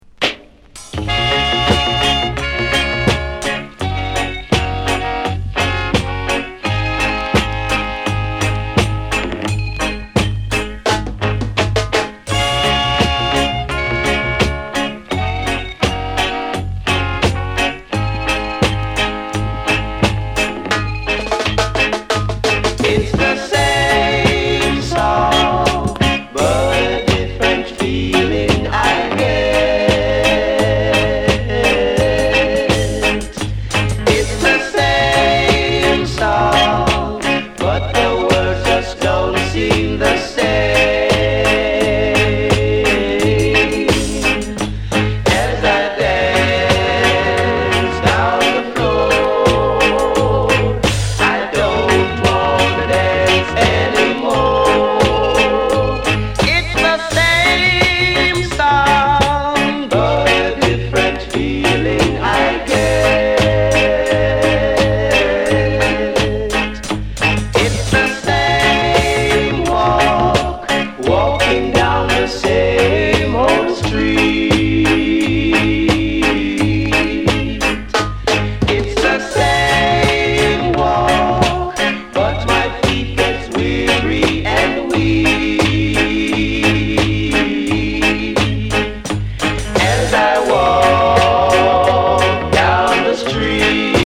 LP]ロックステディーレゲエ